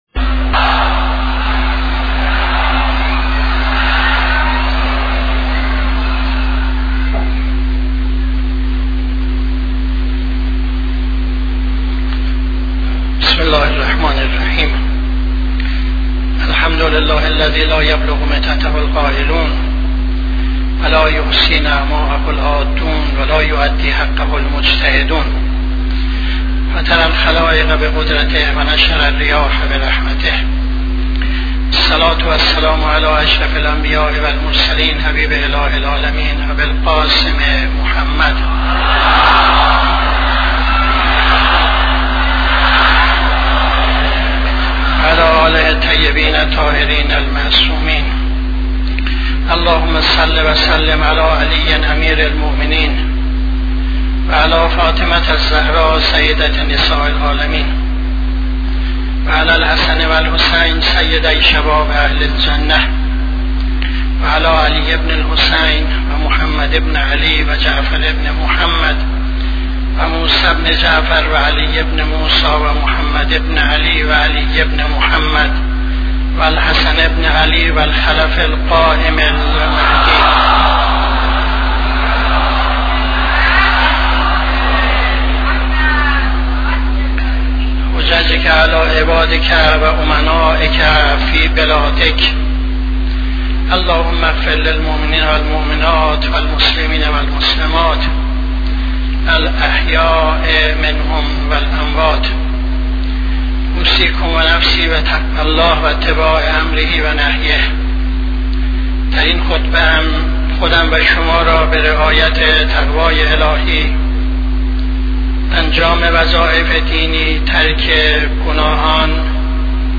خطبه دوم نماز جمعه 27-04-76